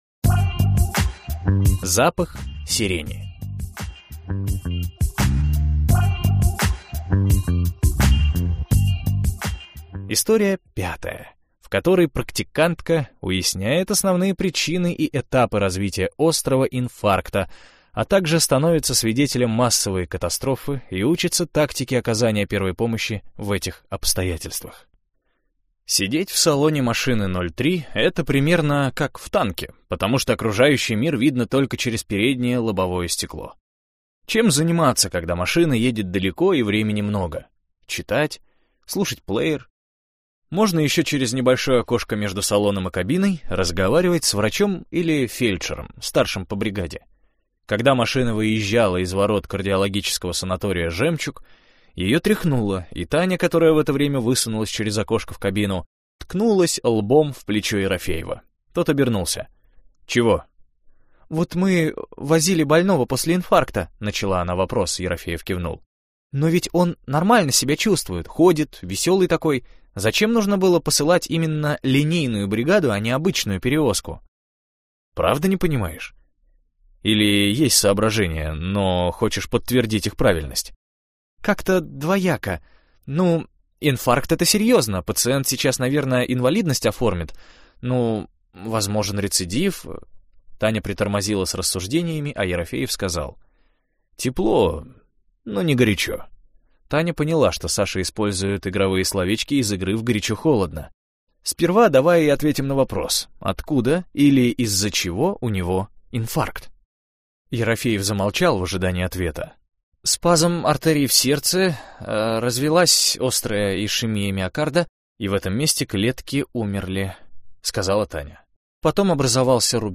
Аудиокнига Фамильные ценности, или Возврату не подлежит | Библиотека аудиокниг